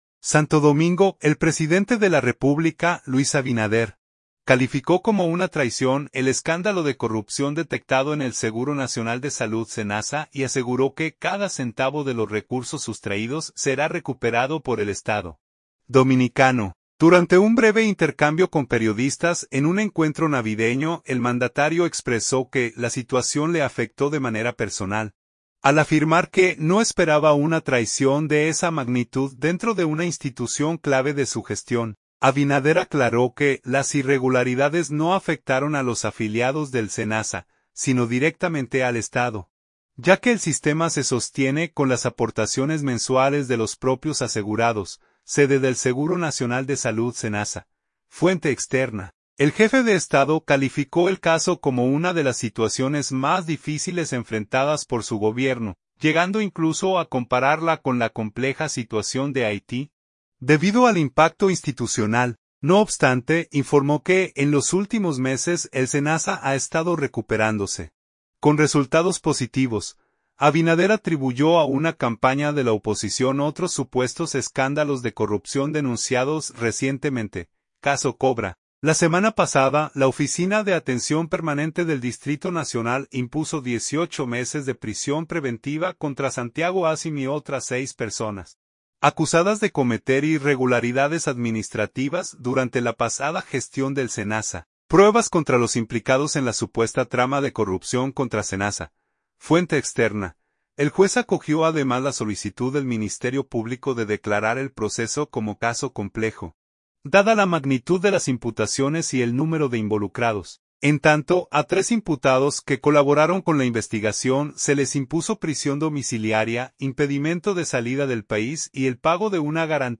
Durante un breve intercambio con periodistas en un encuentro navideño, el mandatario expresó que la situación le afectó de manera personal, al afirmar que no esperaba una traición de esa magnitud dentro de una institución clave de su gestión.